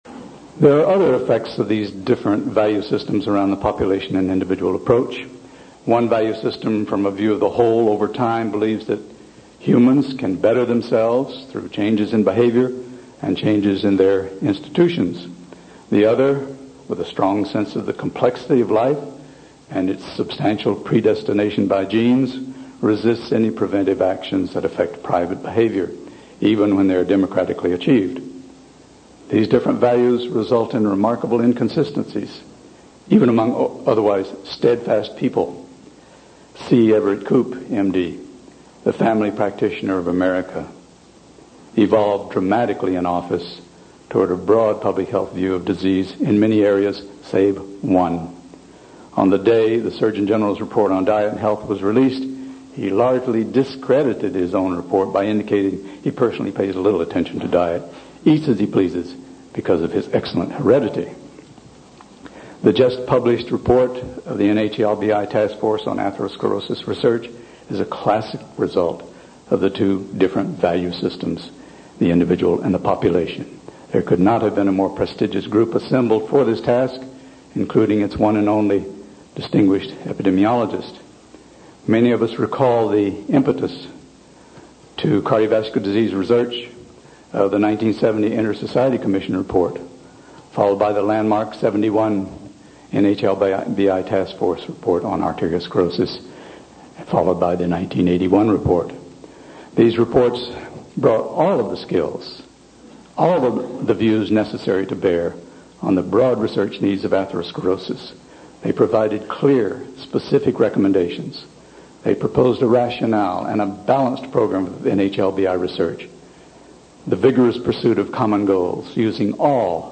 Lecture
at the American Heart Association Meeting in Anaheim in 1991